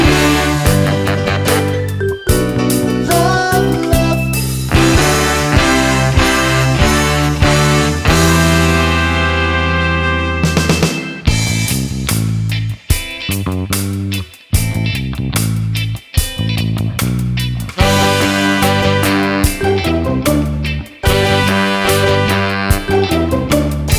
No Saxophone Solo Ska 3:18 Buy £1.50